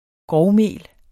Udtale [ ˈgʁɒwˌmeˀl ]